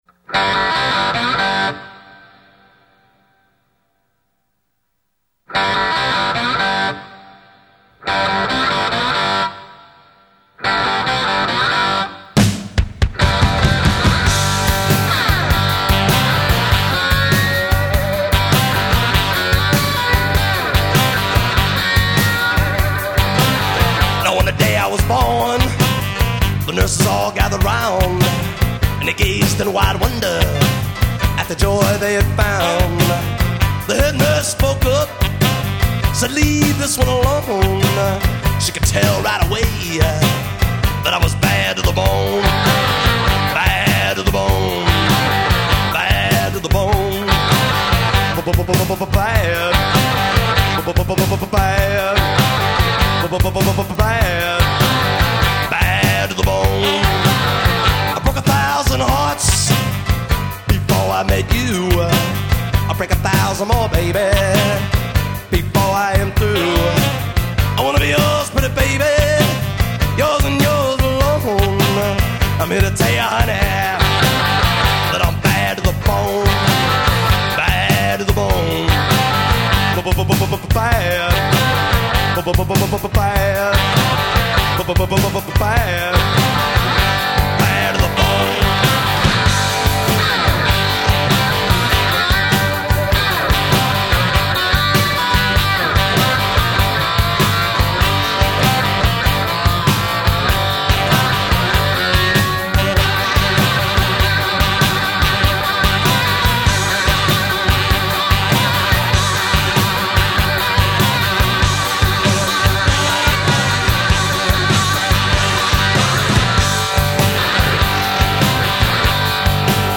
Rock & Roll / Blues – 1982